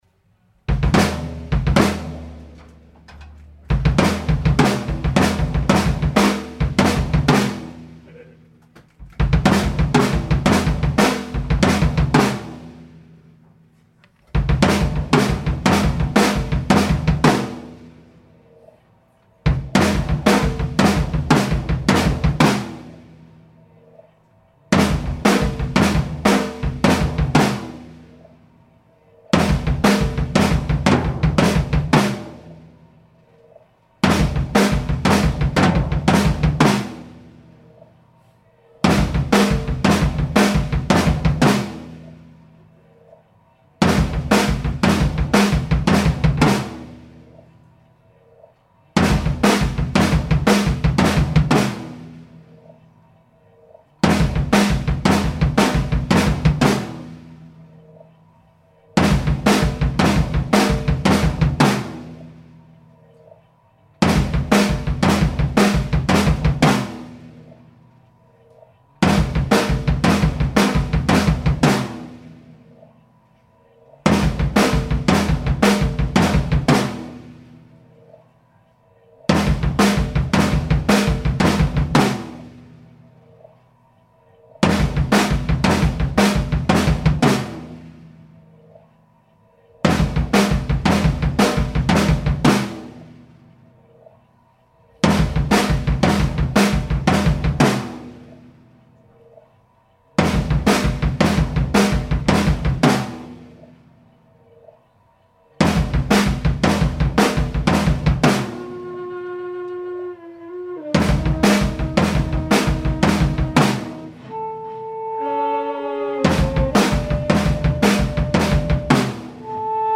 Recorded live at Freddy’s Back Room in Brooklyn, NY
on his portable recorder.
drums
alto saxophone, sub fx
tenor saxophone
an Occupy Wall Street fundraiser
Stereo (Zoom / ProTools)